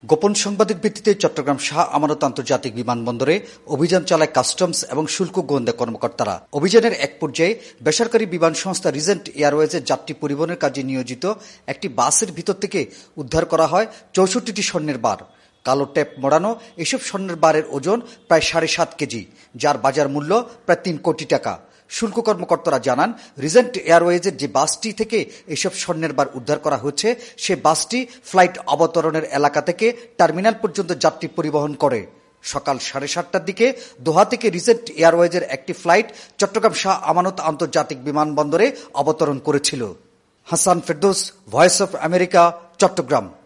চট্টগ্রাম থেকে বিস্তারিত জানাচ্ছেন সংবাদদাতা